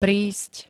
prísť [prísť, prínsť], prijíť, prijdúť dk
Zvukové nahrávky niektorých slov